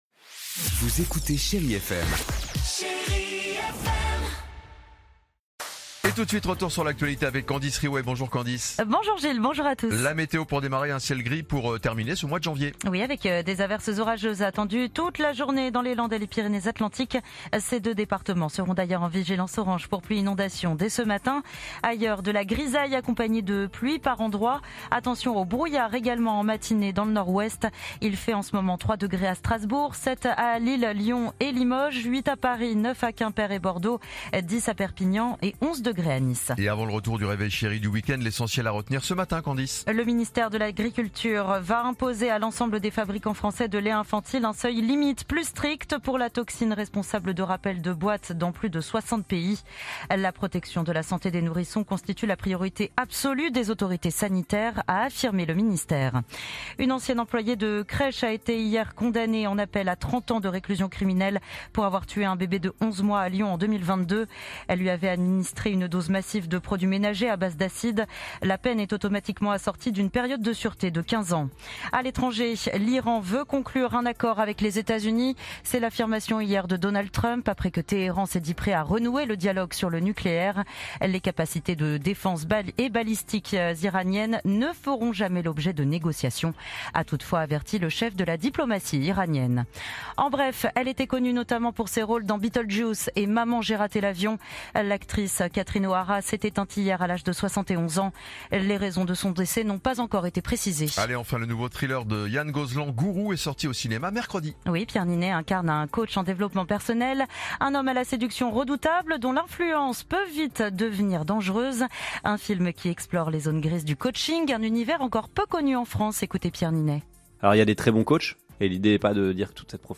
Genres: News